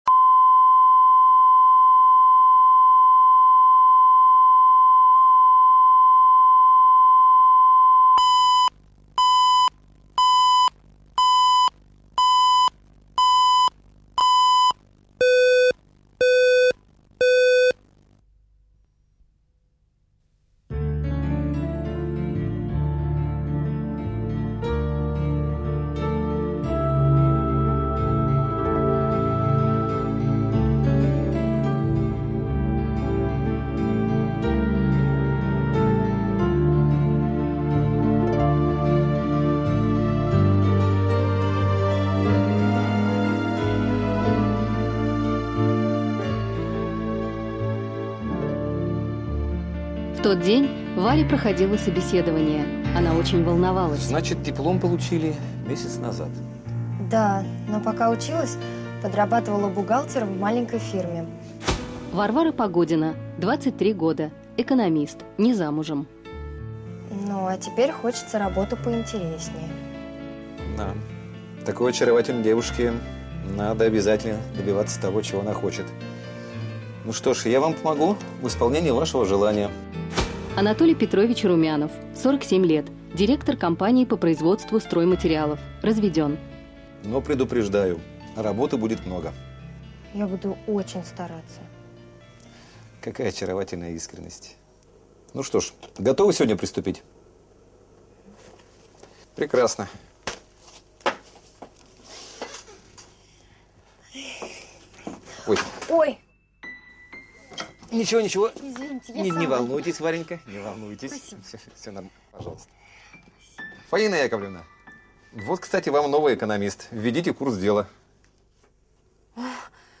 Aудиокнига Офисные страсти Автор Александр Левин. Прослушать и бесплатно скачать фрагмент аудиокниги